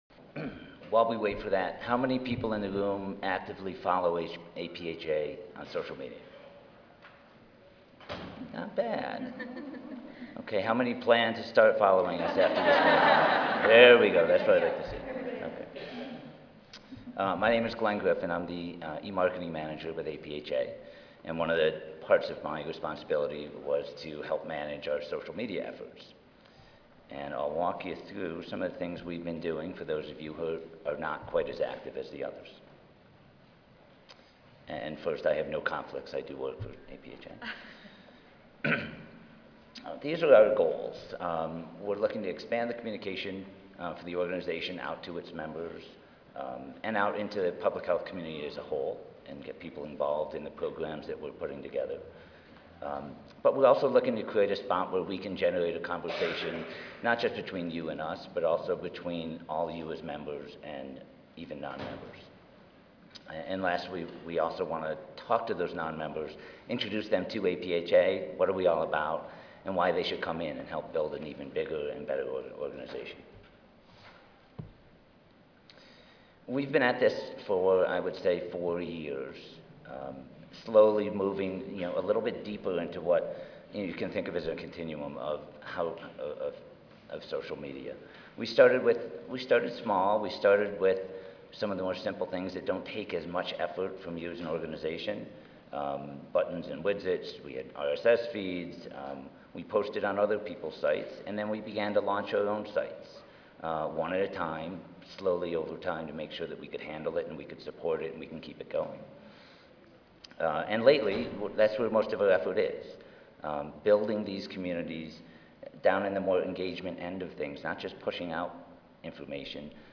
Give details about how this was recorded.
This session is designed to help enhance visibility of public health issues by leveraging social media tools within a communications campaign. The panel will share best practices on how to engage key health bloggers and build a dialogue with a wide audience.